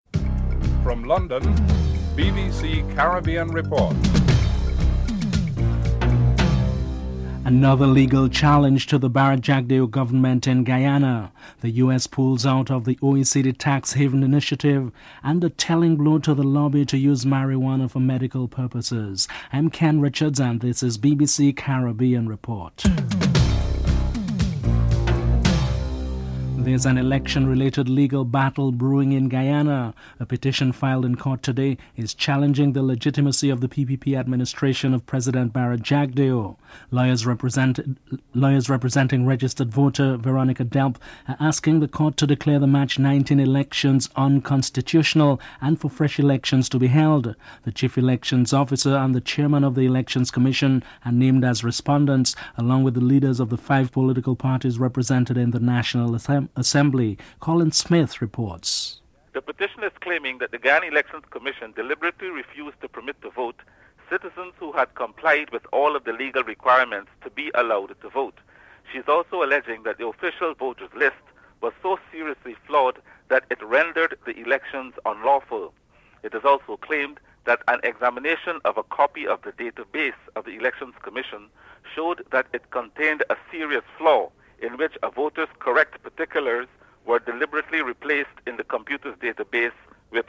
1. Headlines (00:00-00:25)
5. European Union has pledged to forgive all outstanding debts incurred by the world's least developed countries. The Europeans are quiet about the proposed Free Trade Area of the Americas. Washington-based Jamaican Ambassador Richard Bernal is interviewed. (07:16-10:58)